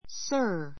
sir A1 弱形 sə r サ～ 強形 sə́ː r サ ～ 名詞 ❶ 先生, あなた, おじさん, もしもし ✓ POINT 目上の人・先生・店の客・見知らぬ人など丁寧 ていねい さが必要な男性に対する呼びかけの敬称.